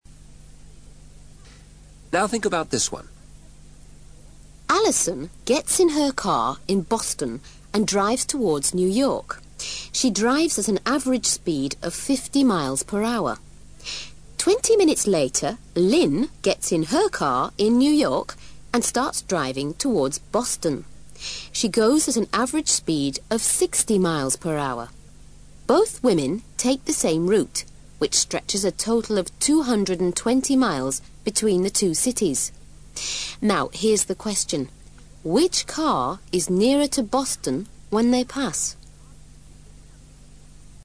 ACTIVITY 312: Now, listen to the psychologist giving you the second problem in logical thinking.